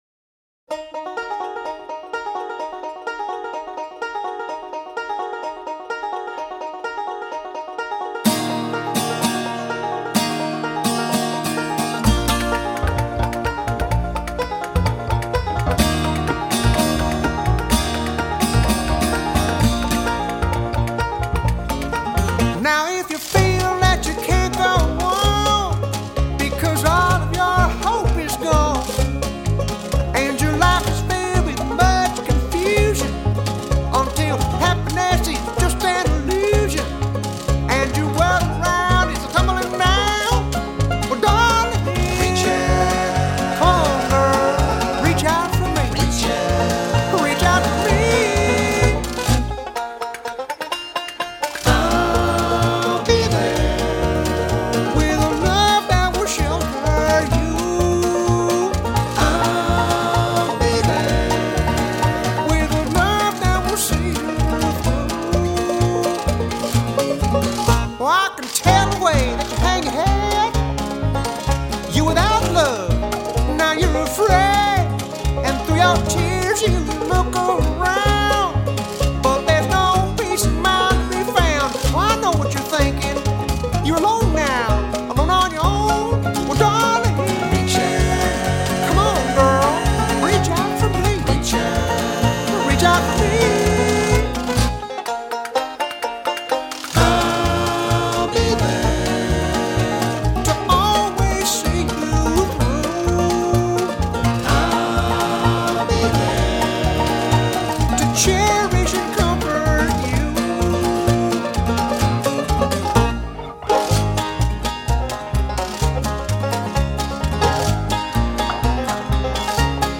banjo
lead vocals